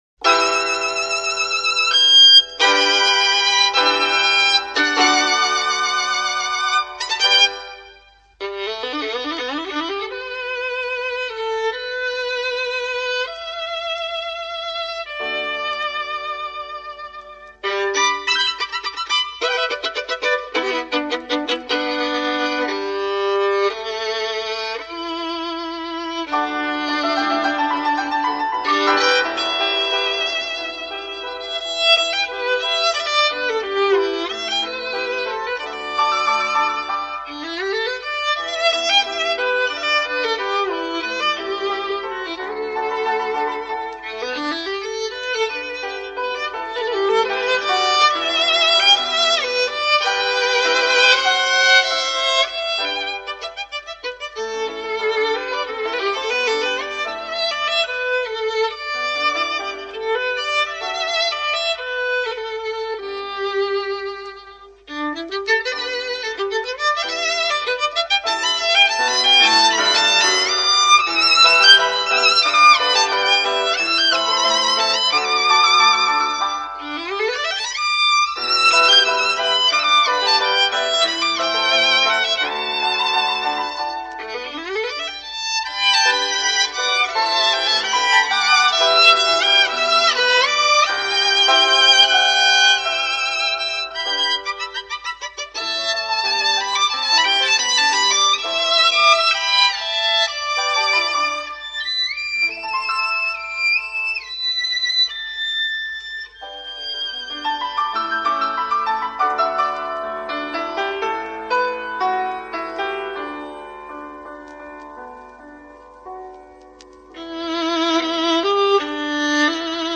小提琴独奏曲·钢琴伴奏
小提琴